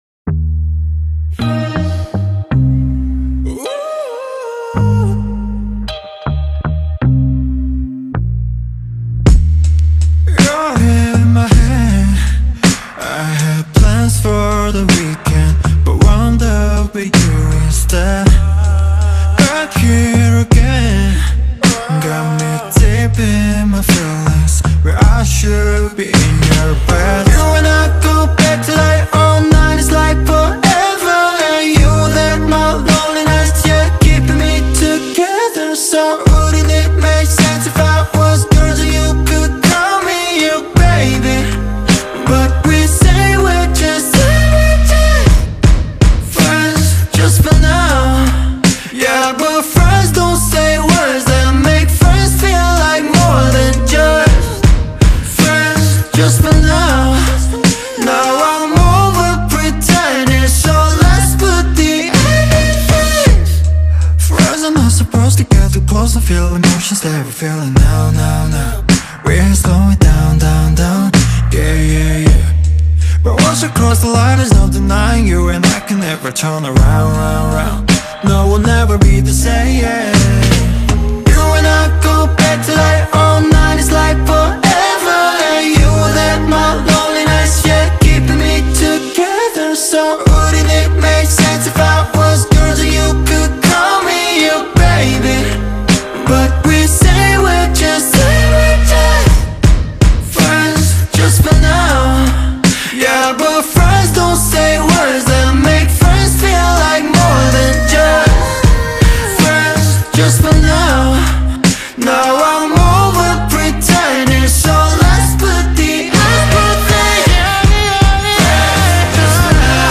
К-ПОП